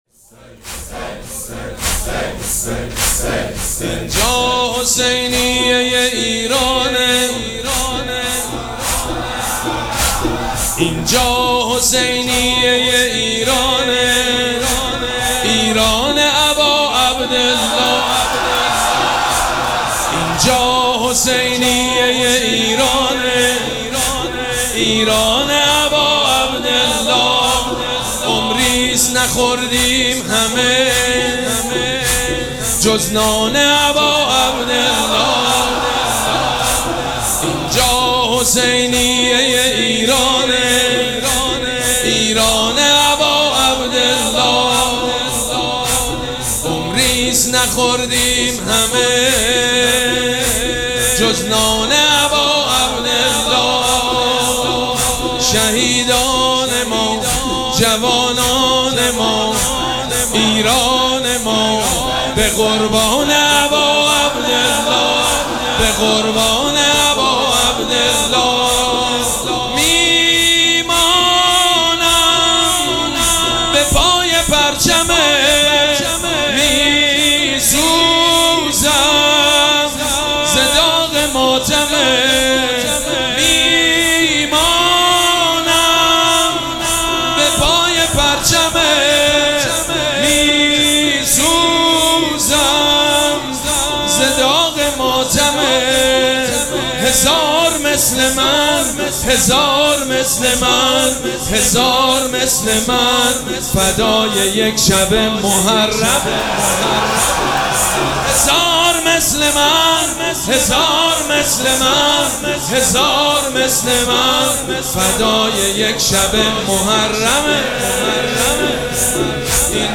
حماسی خوانی مداحان برای ایران/ "اینجا ایران امام حسینه"
بنی‌فاطمه هم در هیئت ریحانه الحسین، در میان سیل جمعیت، حضور پیدا می‌کند.
حالا جمعیت هم یک‌صدا می‌گویند: «به به!»
بنی‌فاطمه بین مردم شوری به راه انداخته و حالا هر بار که نام ایران کنار نام اباعبدالله قرار می‌گیرد، صدای به به مردم بلند می‌شود.